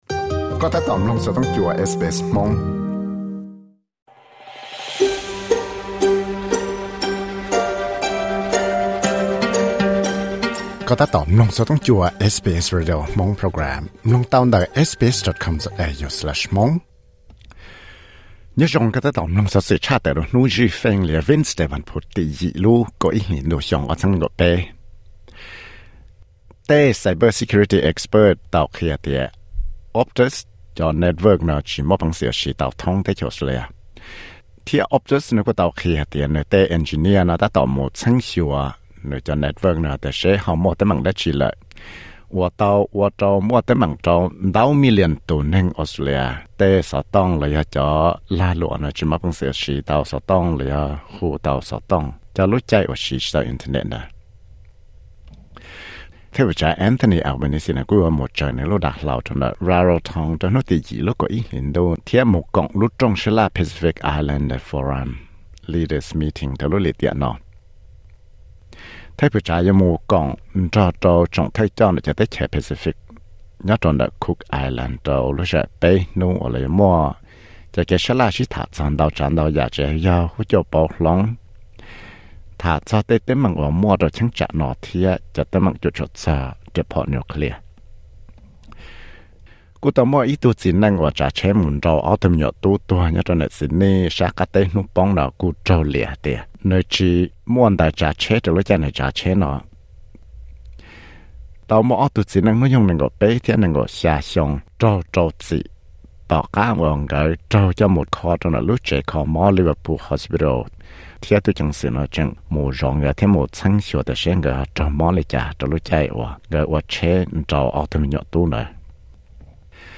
Xov xwm tshaj tawm hnub zwj Feej (Wednesday news 08.11.2023)